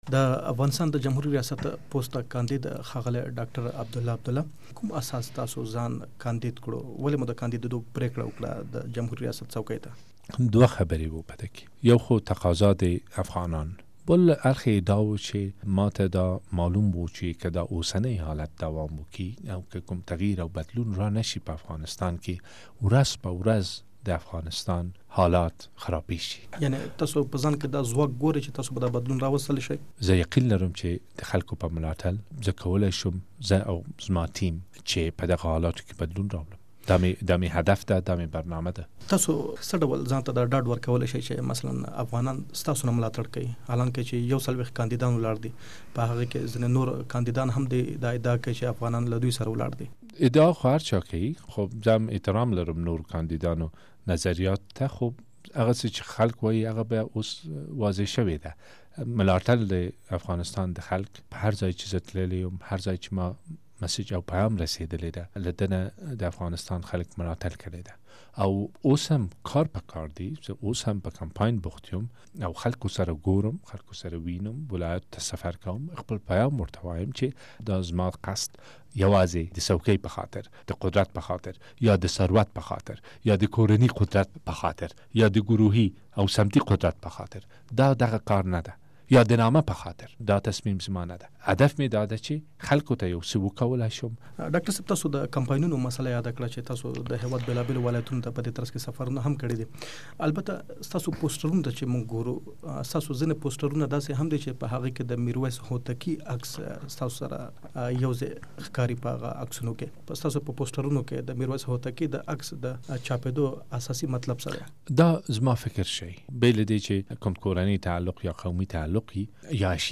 له عبدالله عبدالله سره ځانګړې مرکه واورئ